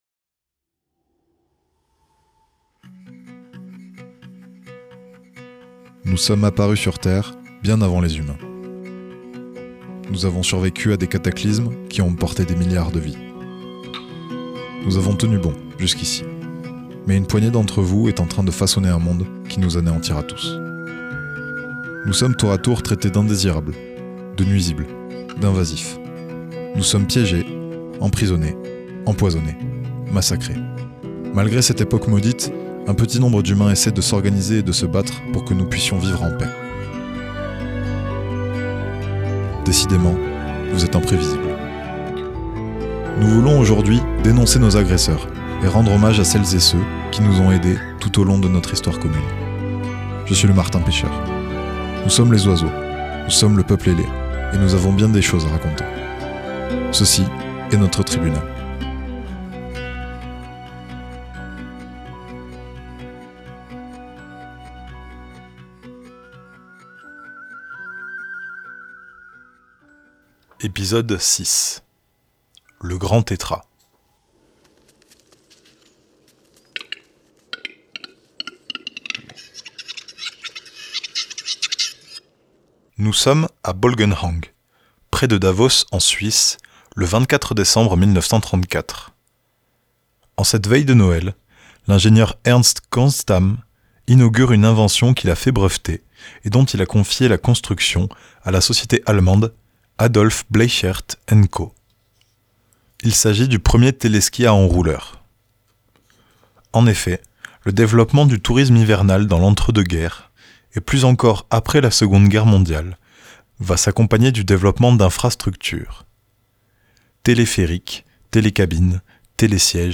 Dans la froideur de la montagne se cachent des formes de vie discrètes et pourtant élégantes dont les voix peinent à couvrir le bruit des machines que les humains ont ramené là.